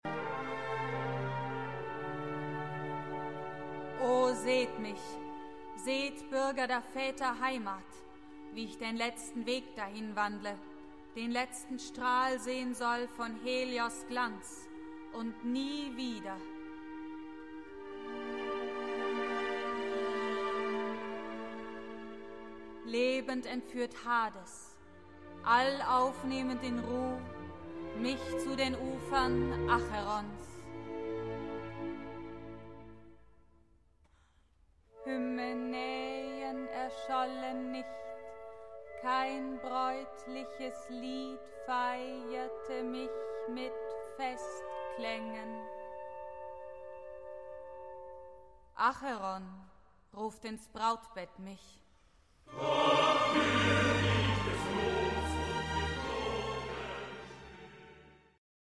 Nella realizzazione definitiva le musiche di scena prevedono tre modalità di esecuzione: le parti liriche, affidate al coro e contrassegnate in genere da un andamento molto sobrio (spesso il coro canta all'unisono, e comunque anche nelle parti polifoniche i cantori seguono sempre tutti insieme lo stesso testo), delle parti di recitativo corale (più volte con l'esplicita indicazione "senza tempo") e delle parti di "melodramma" in cui sono gli attori a recitare sostenuti da un accompagnamento orchestrale: in qualche frangente è previsto che gli attori seguano l'andamento delle note, come si osserva dal seguente passaggio (corrispondente ai vv. 850 ss. dell'originale greco) in cui le parole di Antigone accompagnate da larghe note degli archi a un certo punto lasciano il posto a una recitazione ritmica ("mit den Noten gesprochen") che segue le note di flauti e clarinetti.